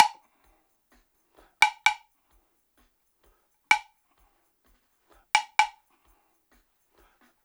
129-BLOCK1.wav